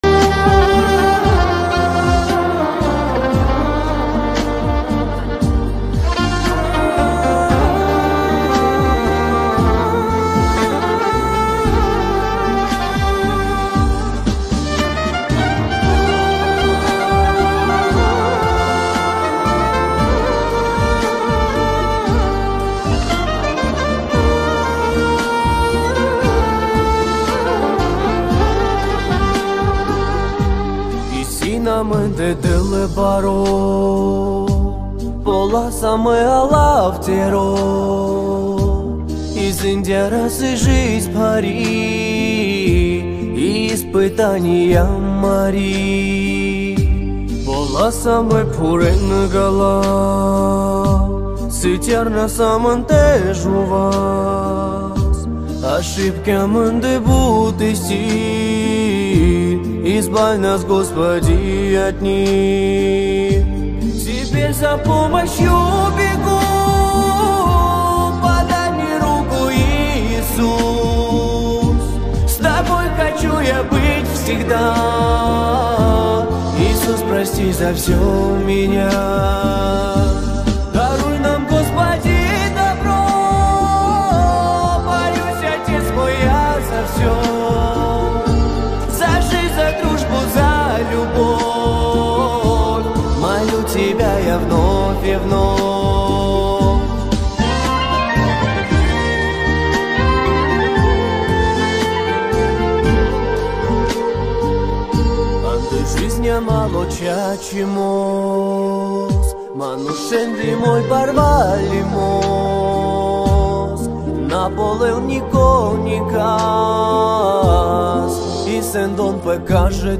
Цыганская Песня